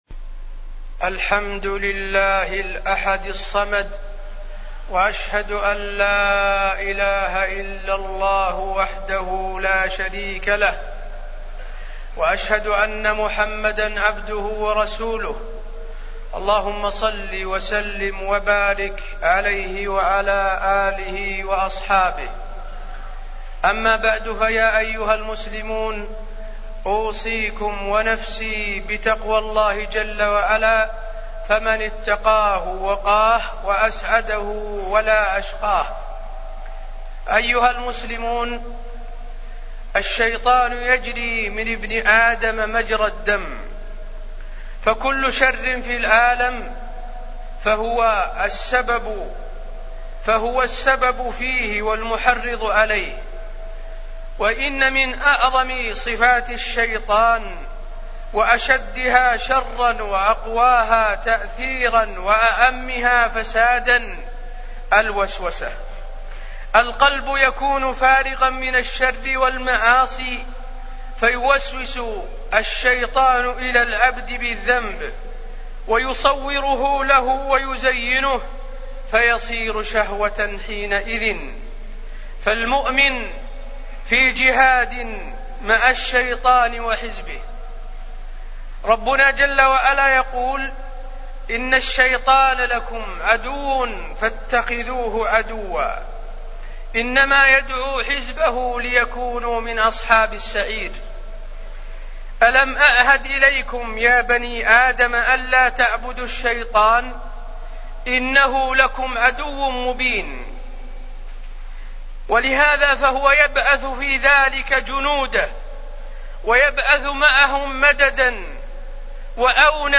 تاريخ النشر ١٦ ذو القعدة ١٤٢٩ هـ المكان: المسجد النبوي الشيخ: فضيلة الشيخ د. حسين بن عبدالعزيز آل الشيخ فضيلة الشيخ د. حسين بن عبدالعزيز آل الشيخ الوسوسة